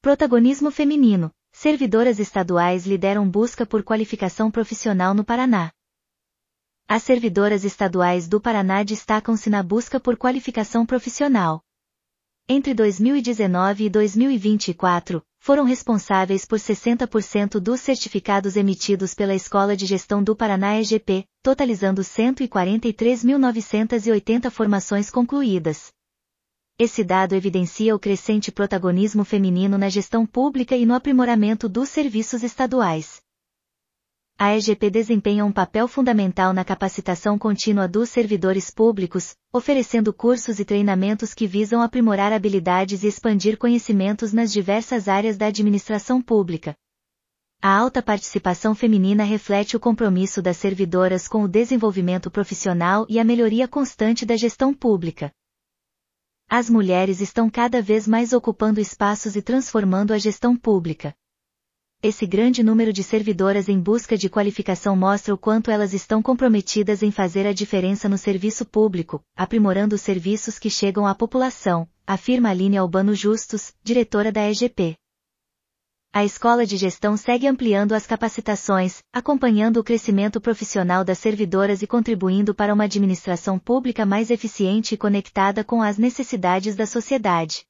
servidoras_estaduais_lideram_busca_audionoticia.mp3